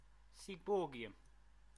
Ääntäminen
IPA : /siːˈbɔrɡiəm/